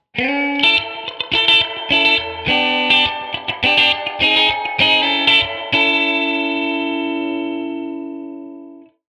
bcc-031814-upbeat-electric-guitar-ident-388+sorry+forgot.mp3